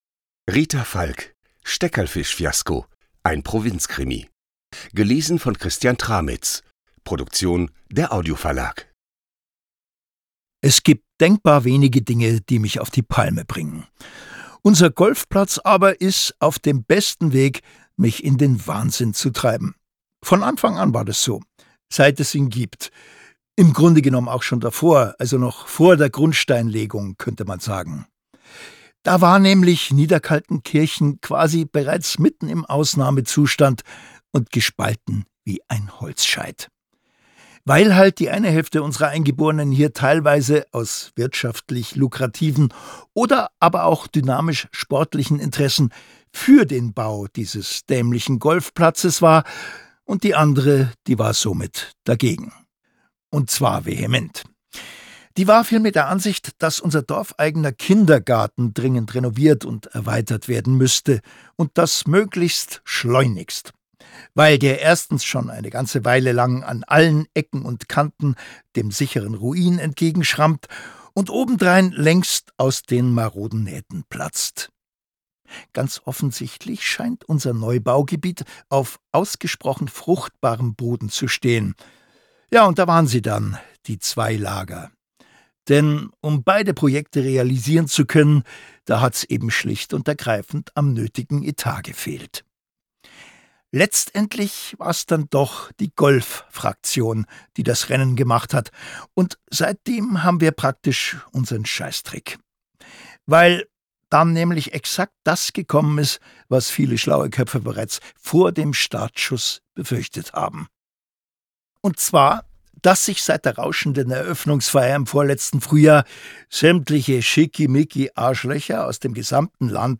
Der zwölfte Fall für den Eberhofer. Ein Provinzkrimi. Ungekürzte Lesung mit Christian Tramitz (6 CDs)
Christian Tramitz (Sprecher)
»Christian Tramitz als Hörbuchsprecher ist unübertroffen!« NDR Kultur
»Wie immer brillant vertont von Comedy-Legende Christian Tramitz.« Ostthüringer Zeitung